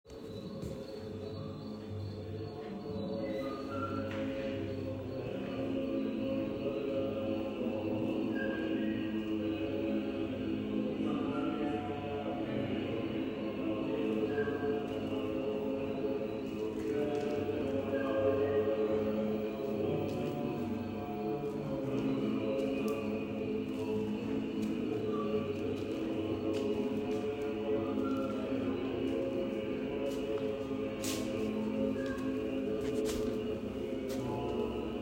individual contribut Description en Chain street birds ContentConcept en ChainsStreetBirds File Date en 2025-09-19 Type en Audio Tier en 8. Sound/music
Tbilisi